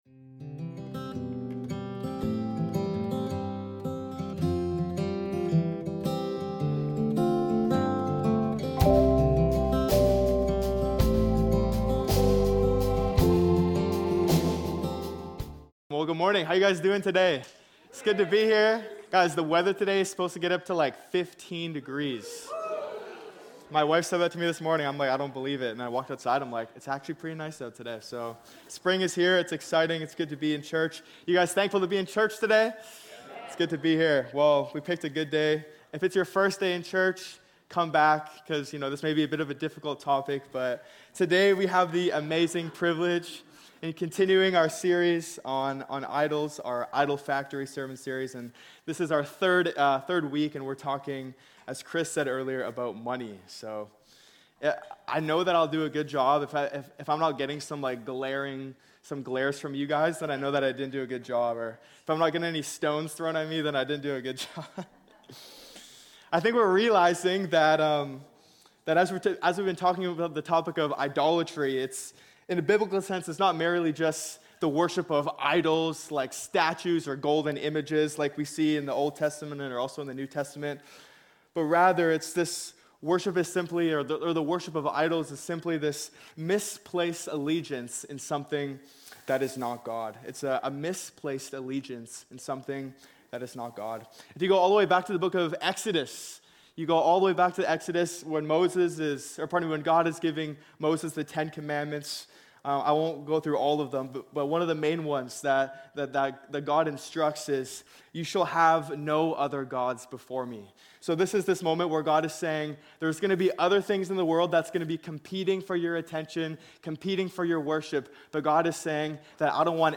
MESSAGES | Redemption Church